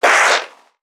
NPC_Creatures_Vocalisations_Infected [113].wav